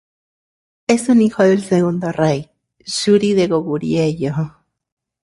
/ˈrei/